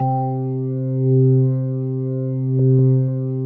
B3LESLIE C 4.wav